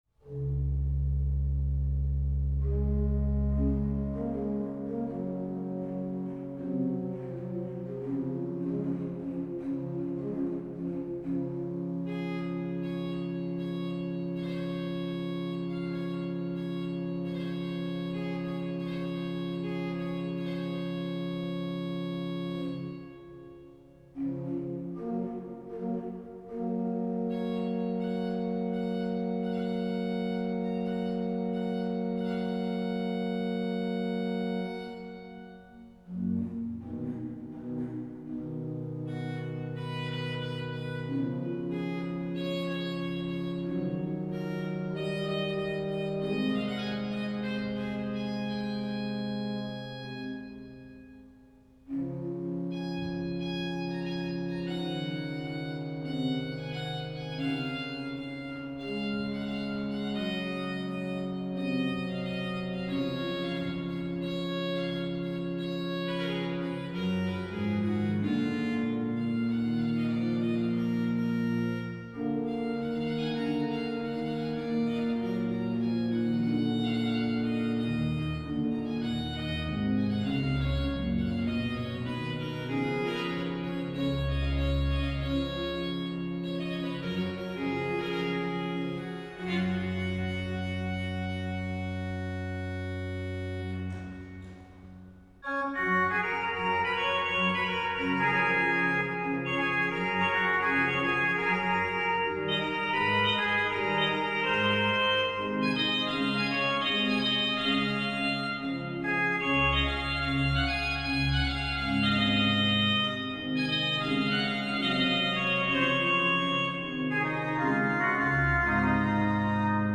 Organista